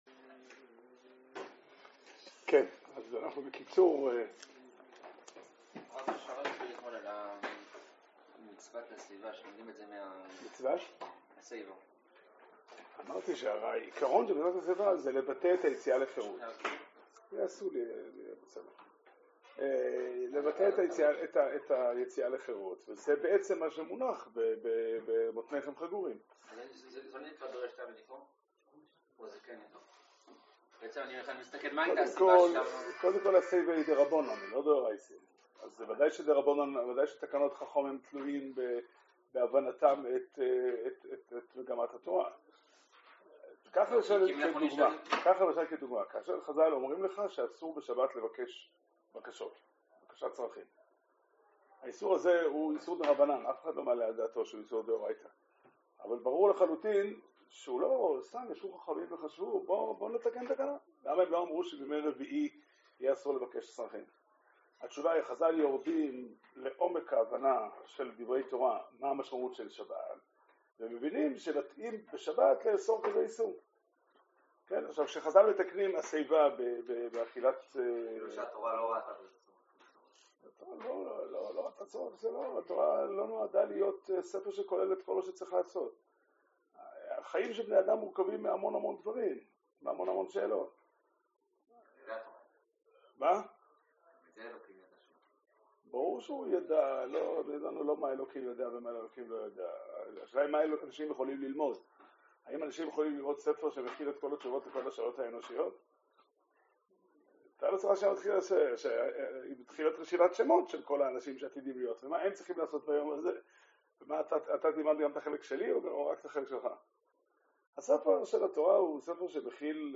שיעור שנמסר בבית המדרש פתחי עולם בתאריך כ״ו באדר תשפ״ג